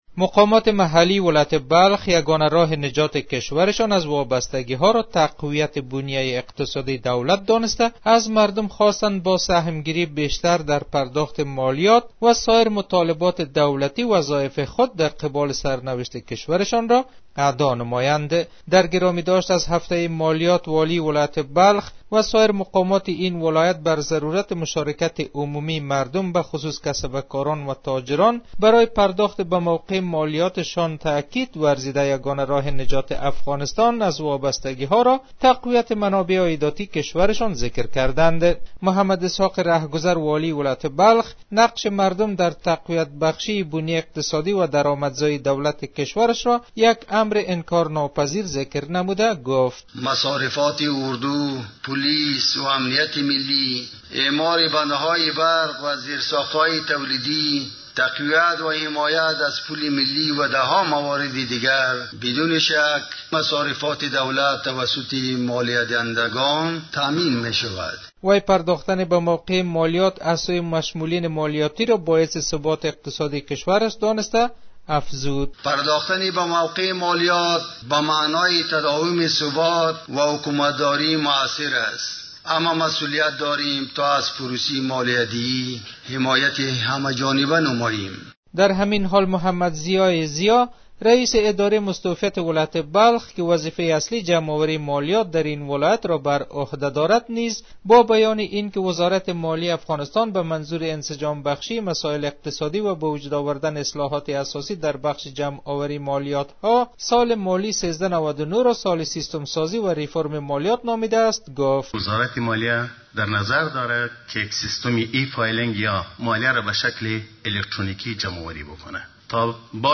خبر / افغانستان